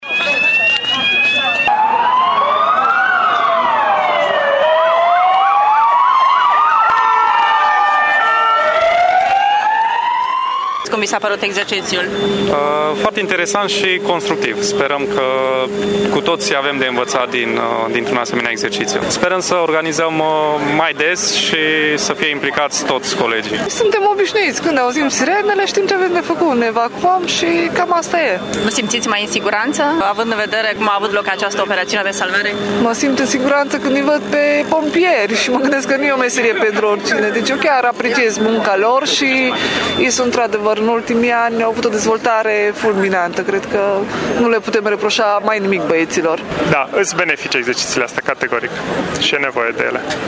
Participanții la simularea de incendiu spun că au avut de învățat din acest exercițiu și îi felicită pe pompieri pentru profesionalism: